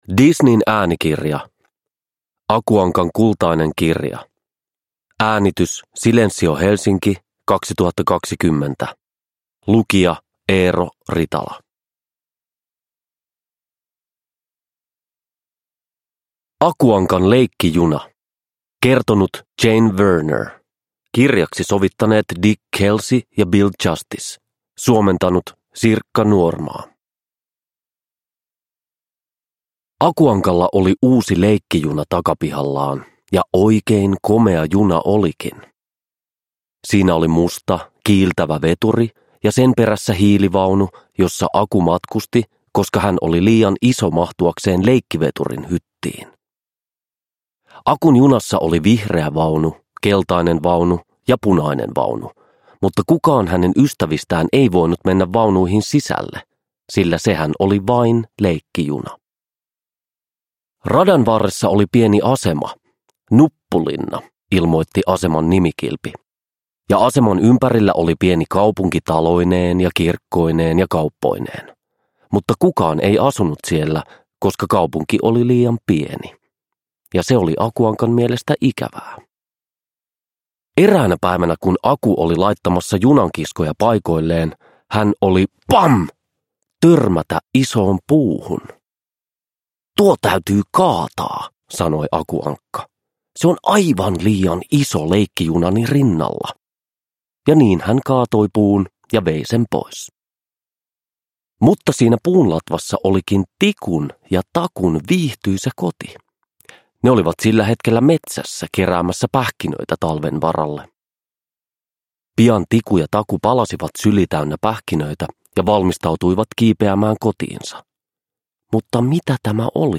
Aku Ankan kultainen kirja – Ljudbok – Laddas ner
Uppläsare: Eero Ritala